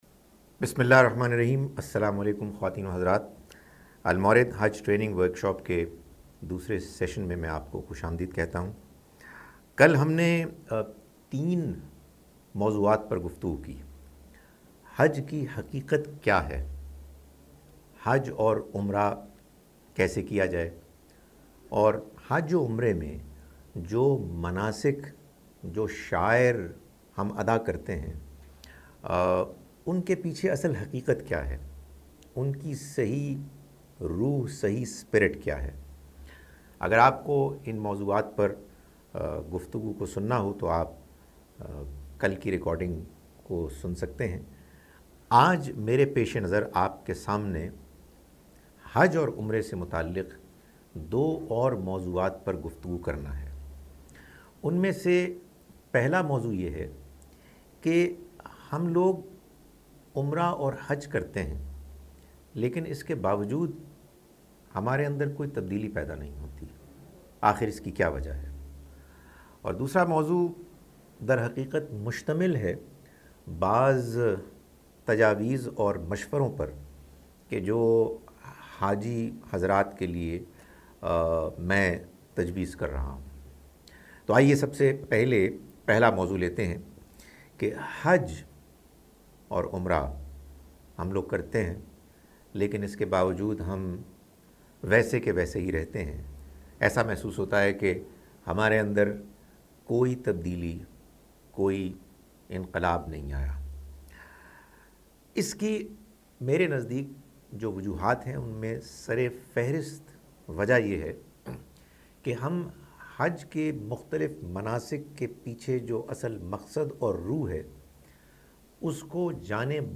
Participants also asked their questions live during the workshop.